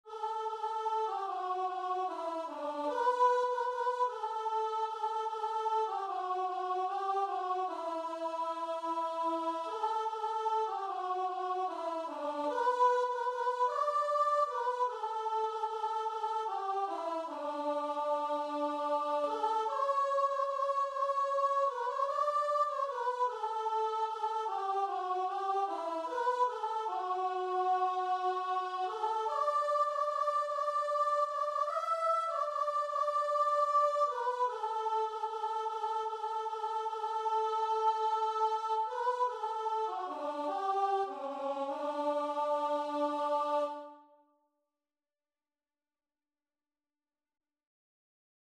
6/8 (View more 6/8 Music)
Classical (View more Classical Guitar and Vocal Music)